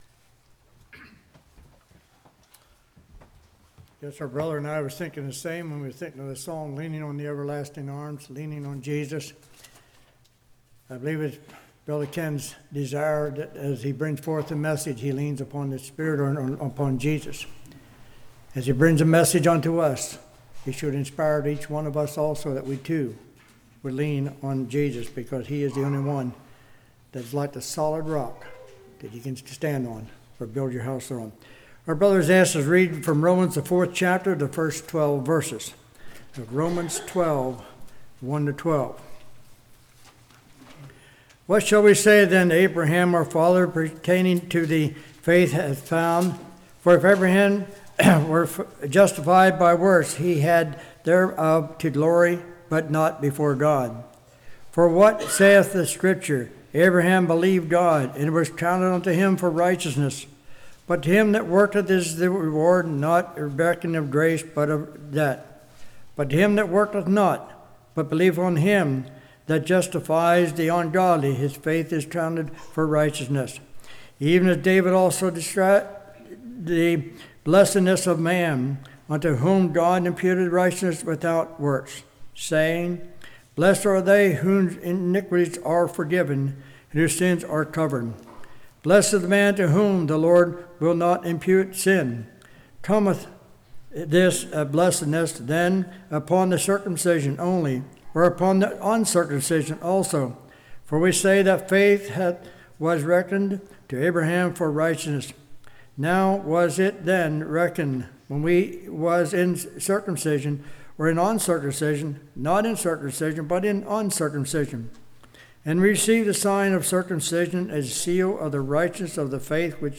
Romans 4:1-12 Service Type: Evening How as Abraham made righteous?